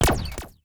UIClick_Laser Double Impact 02.wav